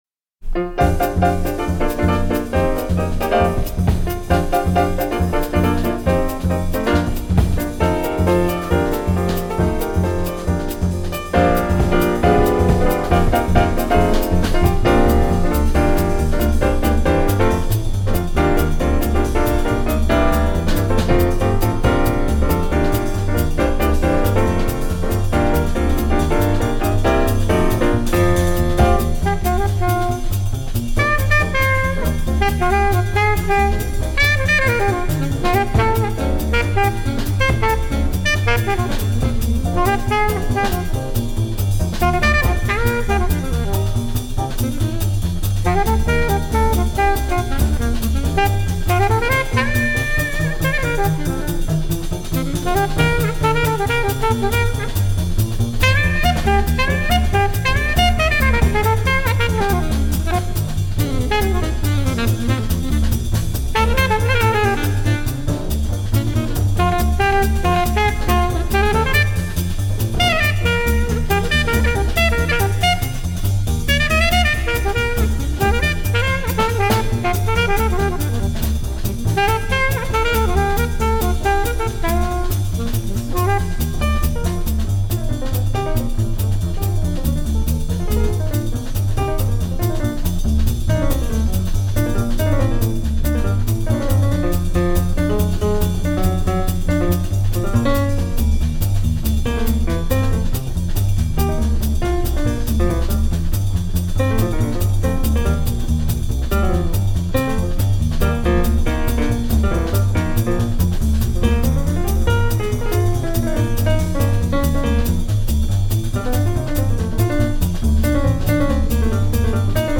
piano
alto saxophone
drums
bass
COOL JAZZ